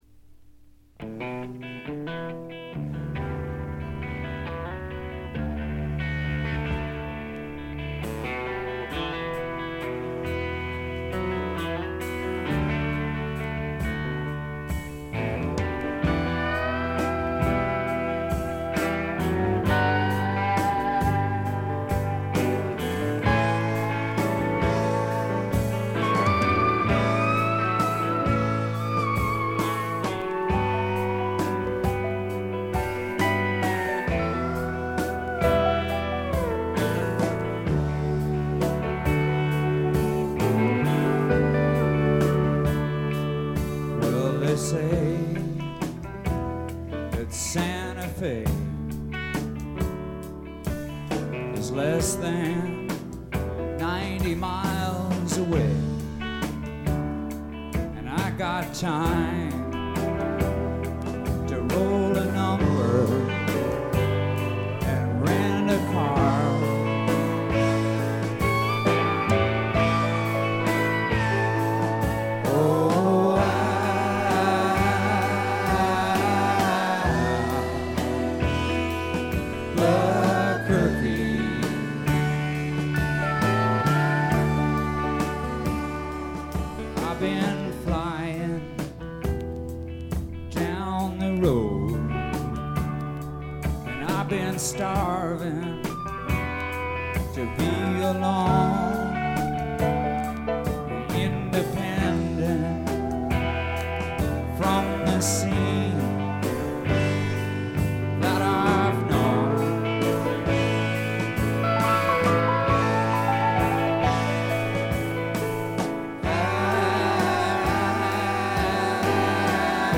特に目立つノイズはありません。
試聴曲は現品からの取り込み音源です。
steel guitar